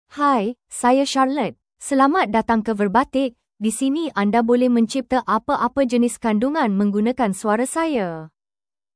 FemaleMalay (Malaysia)
CharlotteFemale Malay AI voice
Charlotte is a female AI voice for Malay (Malaysia).
Voice sample
Listen to Charlotte's female Malay voice.
Charlotte delivers clear pronunciation with authentic Malaysia Malay intonation, making your content sound professionally produced.